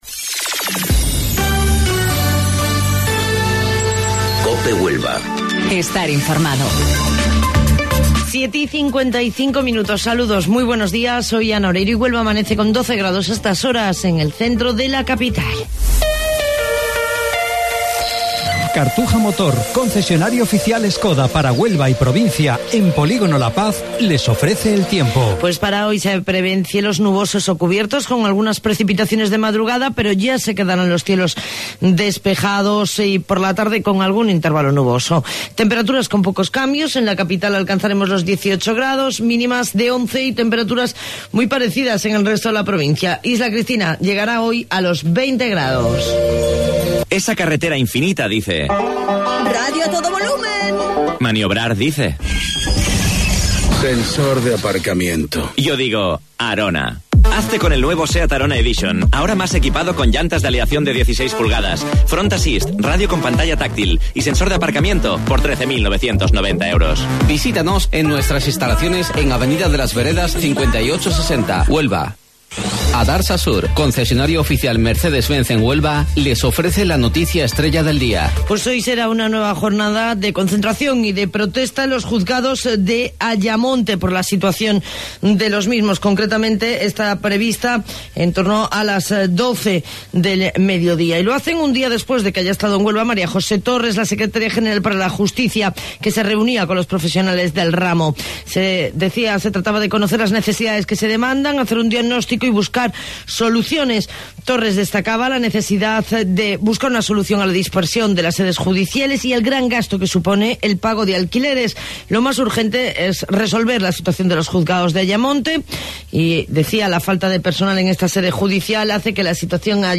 AUDIO: Informativo Local 07:55 del 26 de Abril